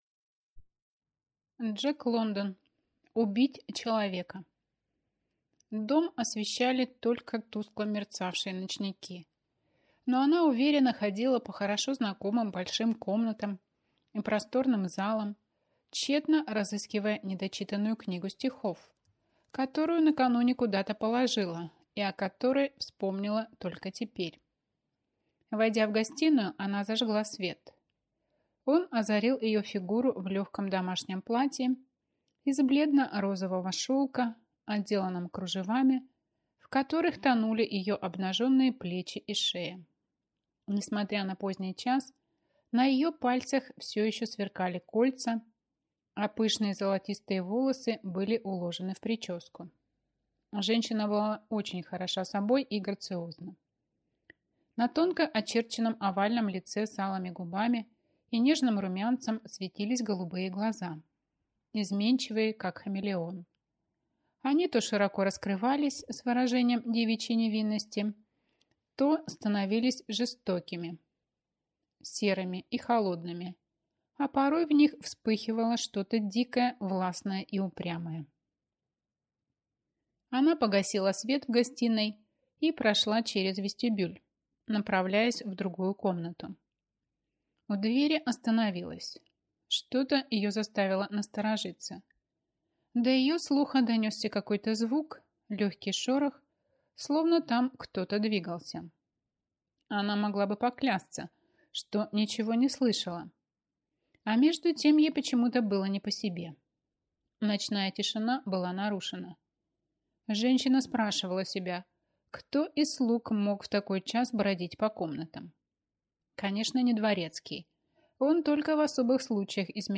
Аудиокнига Убить человека | Библиотека аудиокниг